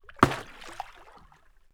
Water_22.wav